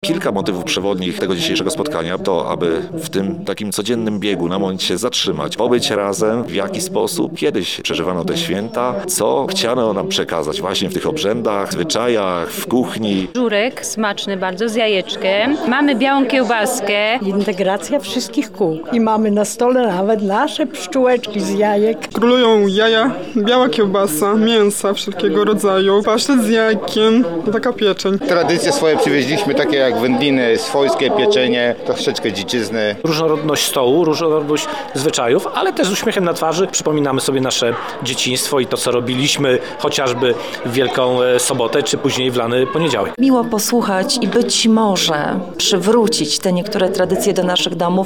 Posłuchaj materiału reportera: